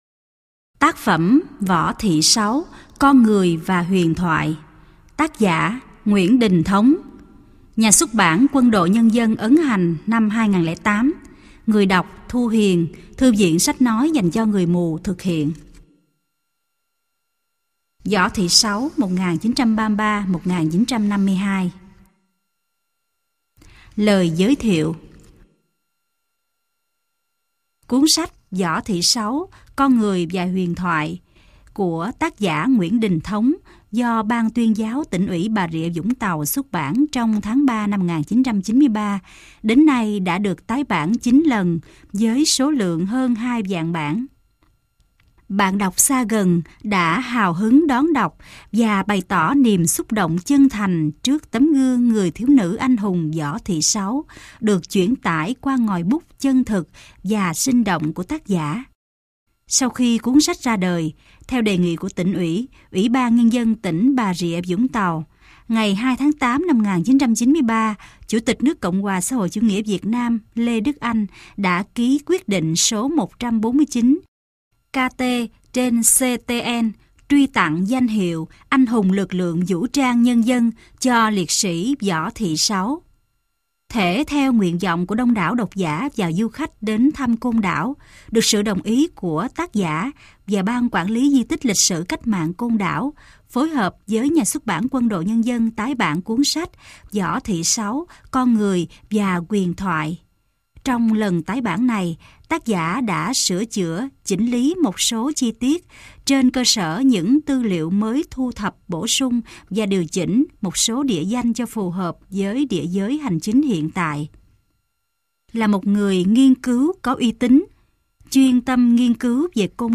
Sách nói Võ Thị Sáu - Con Người Và Huyền Thoại - Nguyễn Đinh Thống - Sách Nói Online Hay